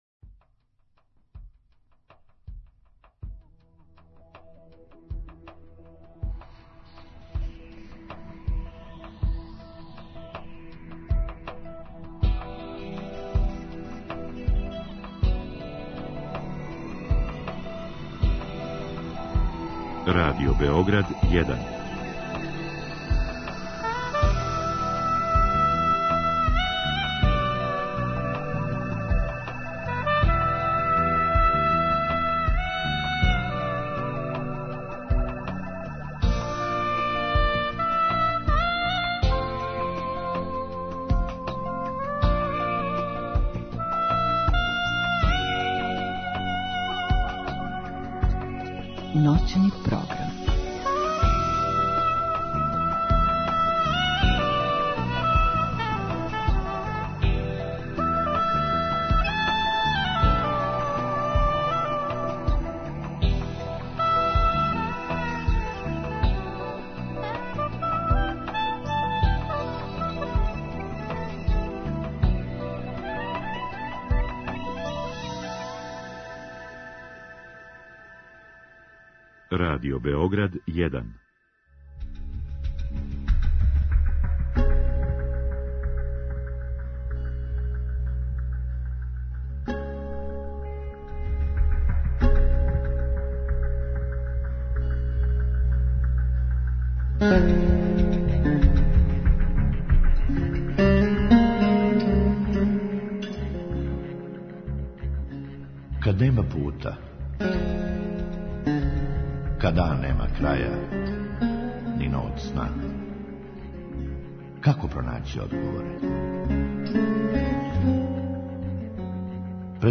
Други сат предвиђен је за укључење слушалаца, који у директном програму могу поставити питање госту.